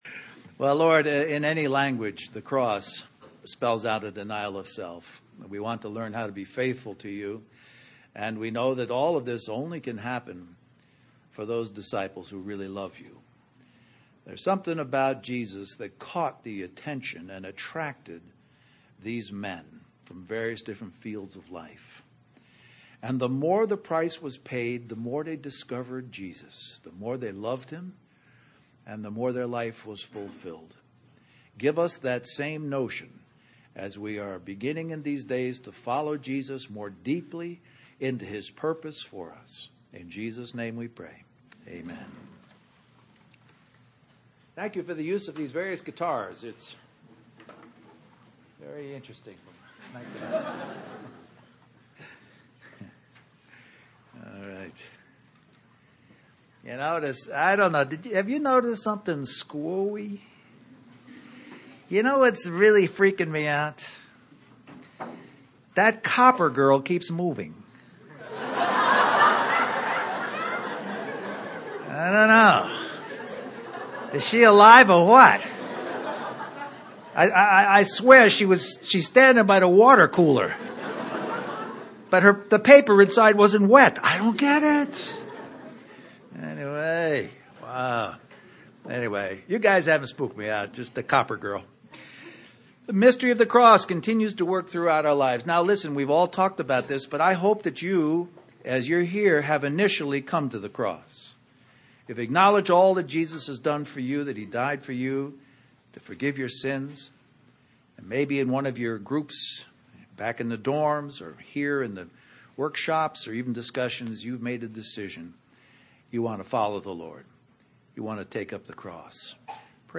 Toronto Summer Youth Conference We apologize for the poor quality audio